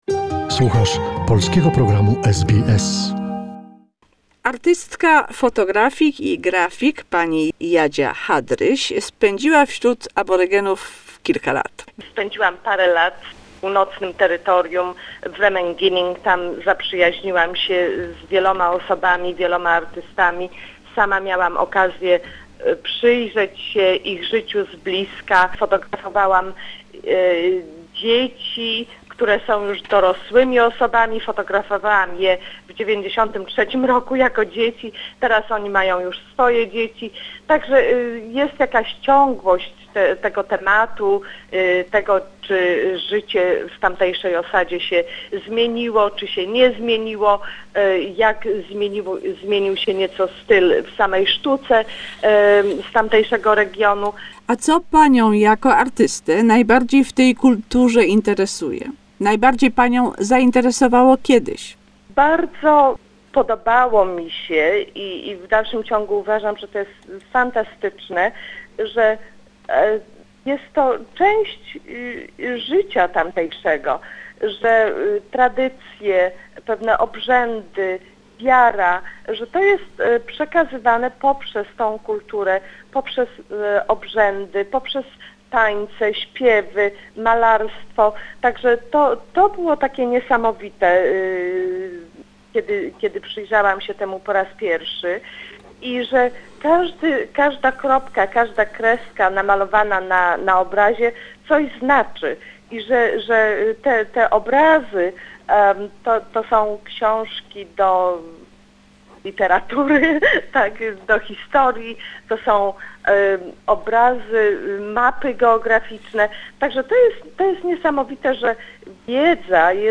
Conversation about Aboriginal art with the graphic artist and photographer